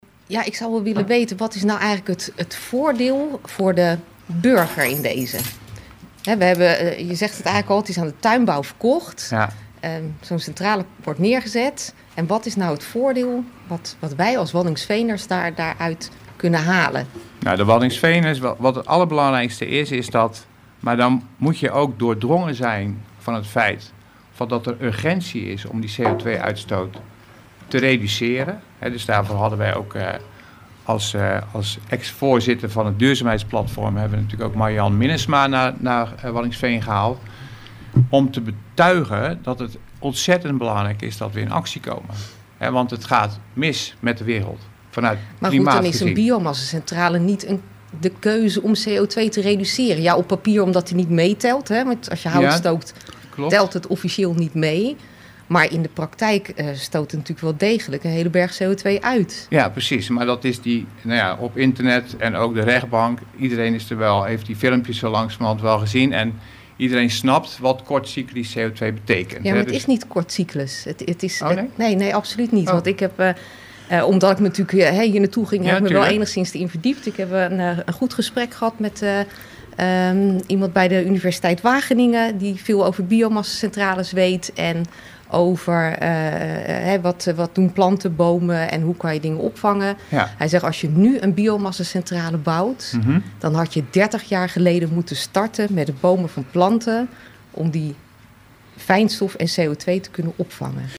Interview bij RTW over biomassacentrales